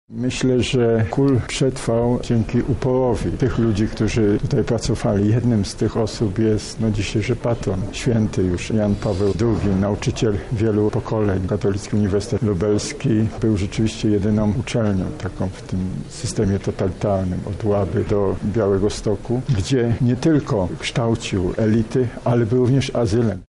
– mówi Jarosław Szarek, prezes Instytutu Pamięci Narodowej.